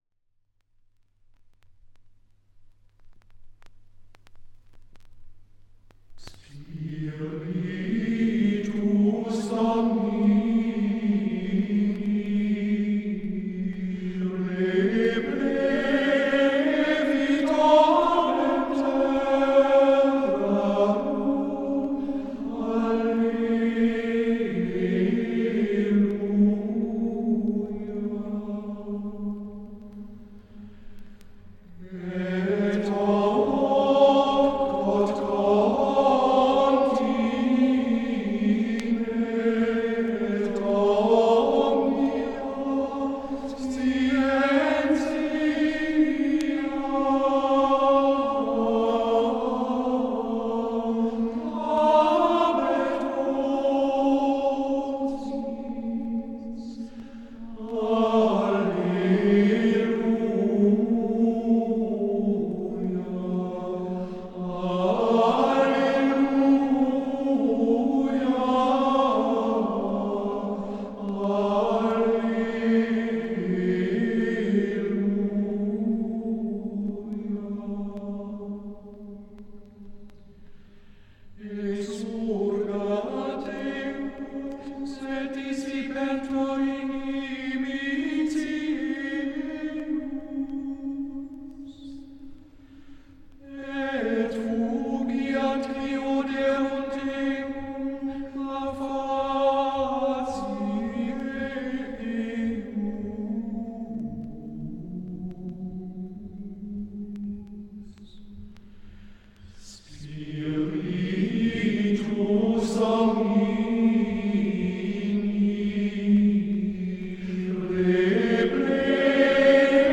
Gesang: Schola gregoriana
aufgenommen in der Klosterkirche Knechtsteden